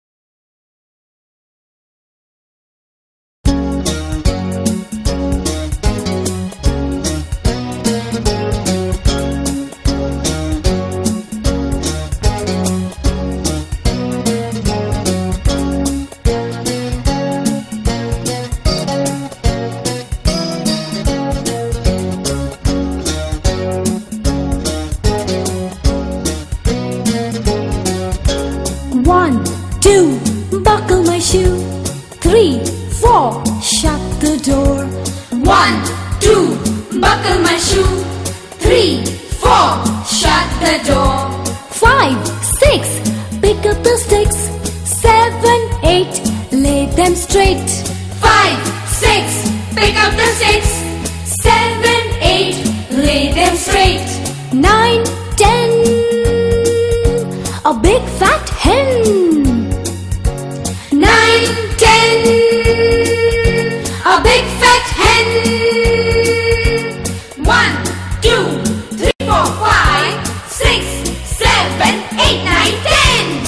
Popular English nursery rhymes for your toddler.